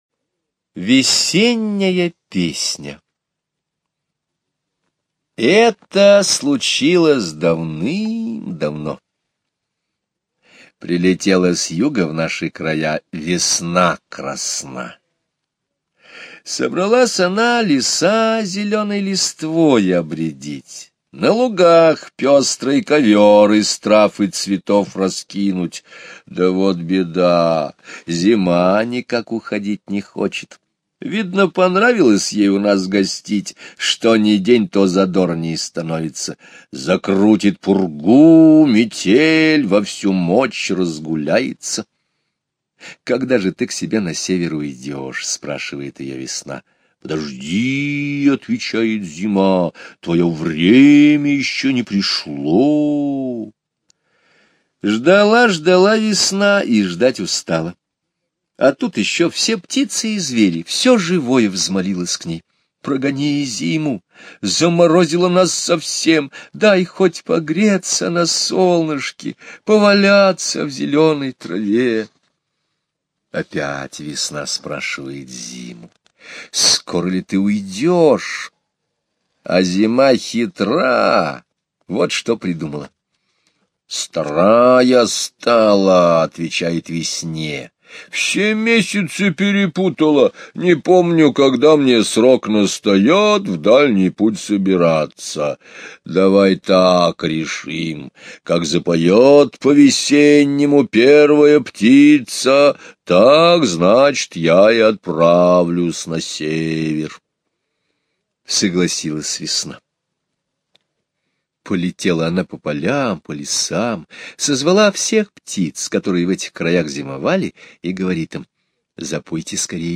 Слушайте Весенняя песня - аудио рассказ Скребицкого Г. Рассказ о том, как Зима не хотела уходить пока услышит настоящую весеннюю песню птиц.